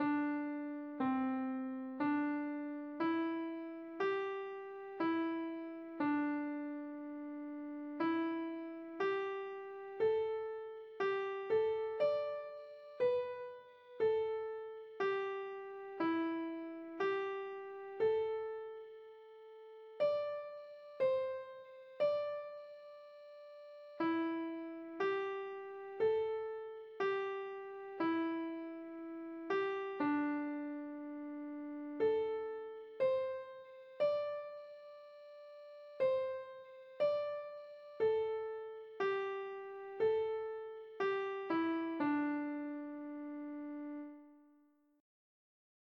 サリンドの共鳴音.mp3.ogg